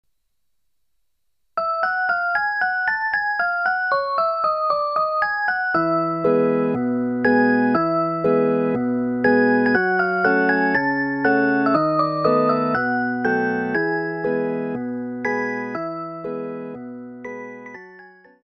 冬の雰囲気にぴったりのオルゴール風小曲集
「夢のなかで」以外は、すべてビブラホン・電子ピアノ・オルガンパートで構成されています。